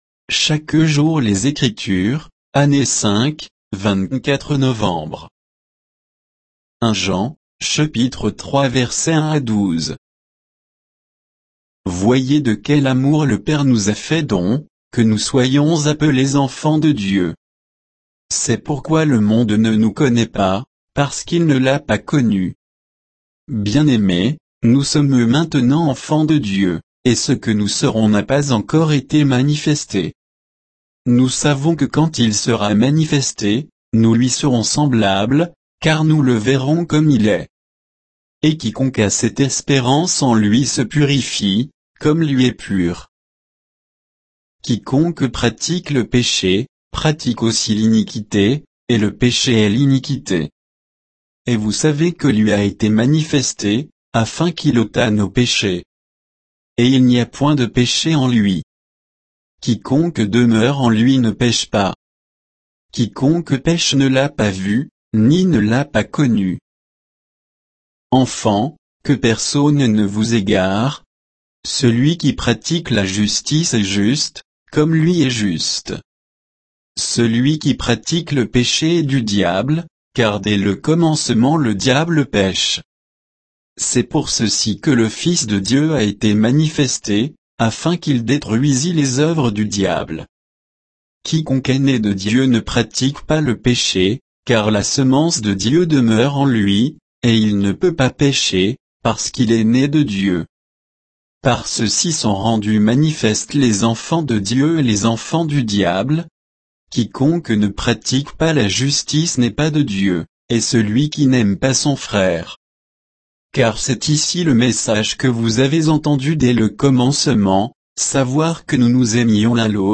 Méditation quoditienne de Chaque jour les Écritures sur 1 Jean 3